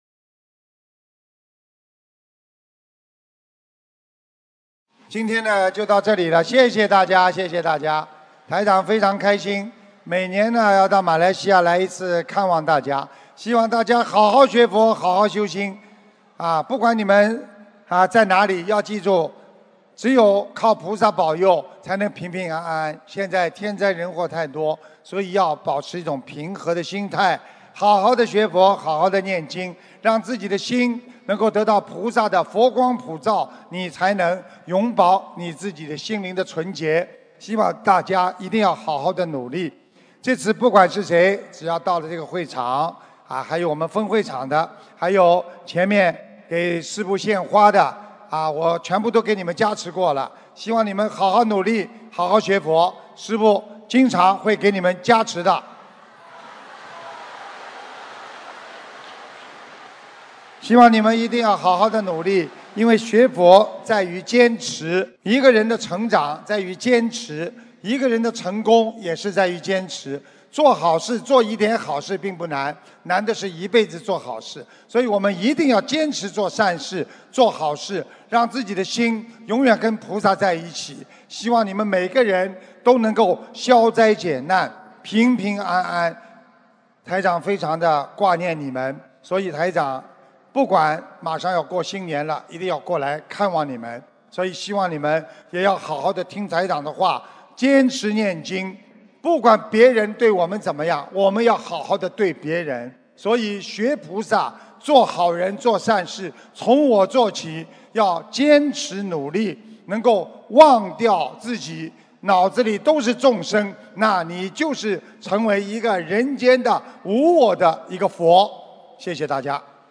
2018年12月30日吉隆坡法会感人结束语-经典开示节选